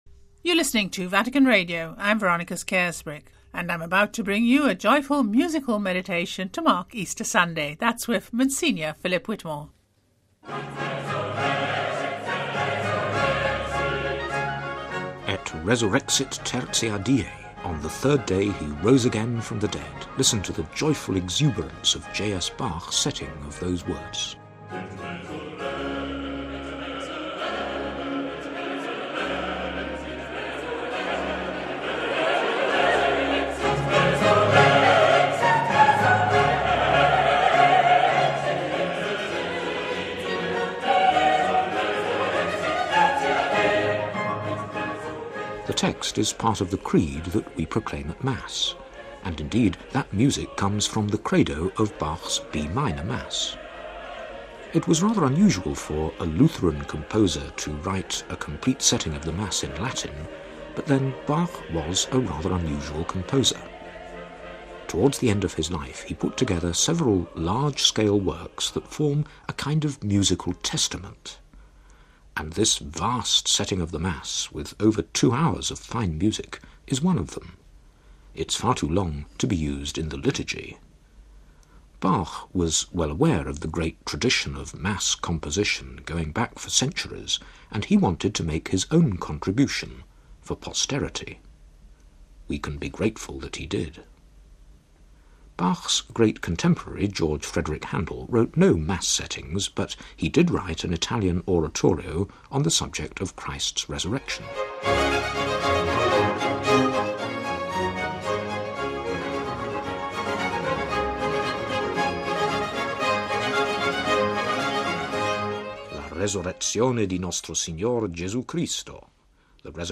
Et Resurrexit tertia die: J.S. Bach's joyful exuberance
musical meditation